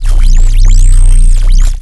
sci-fi_electric_pulse_hum_06.wav